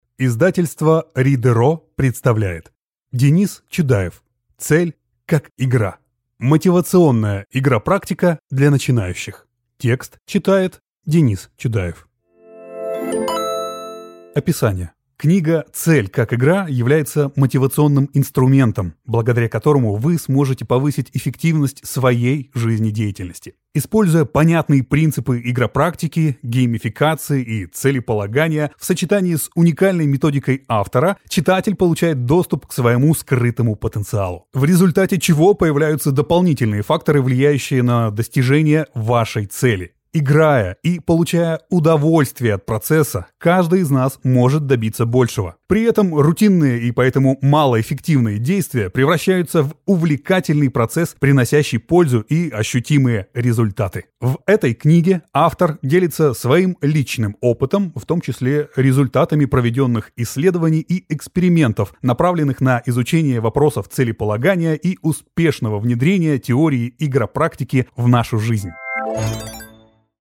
Аудиокнига Цель как игра. Мотивационная игропрактика для начинающих | Библиотека аудиокниг